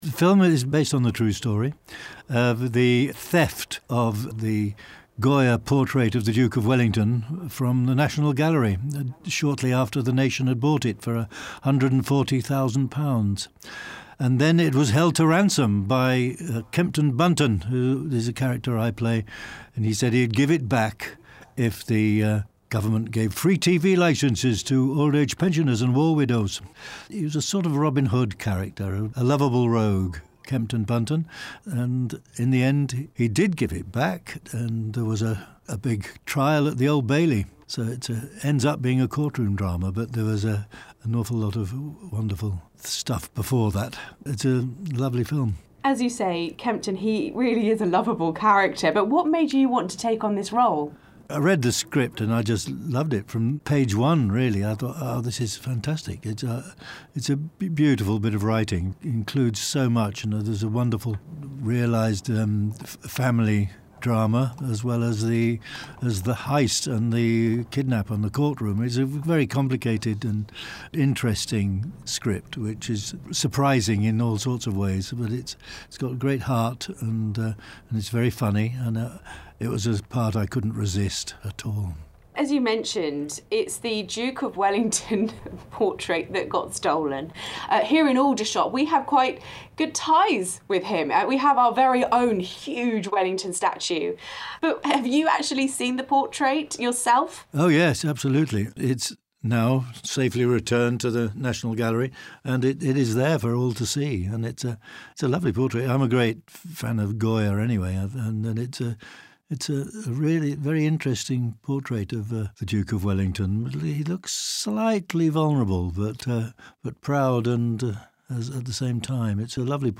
BFBS Radio bring you interviews and chats with some of the big names from the movie industry.
An interview with Jim Broadbent